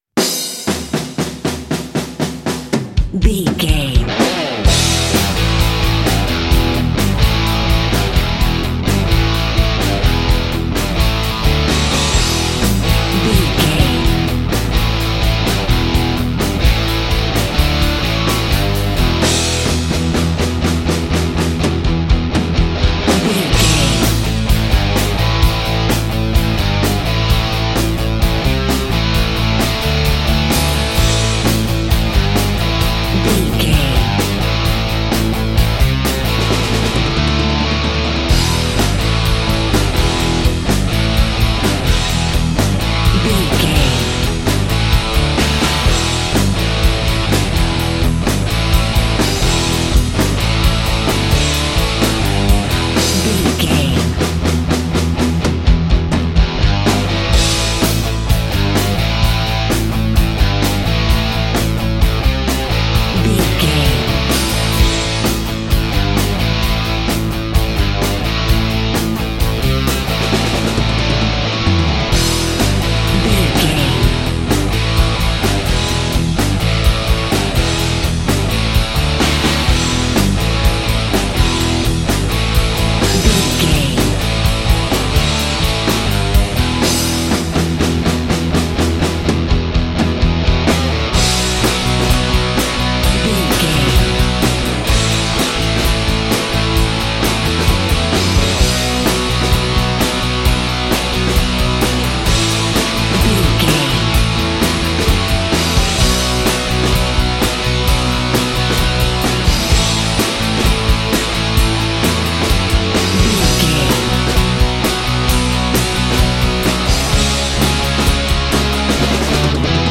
Ionian/Major
electric guitar
drums
bass guitar
Sports Rock
hard rock
lead guitar
aggressive
energetic
intense
powerful
nu metal
alternative metal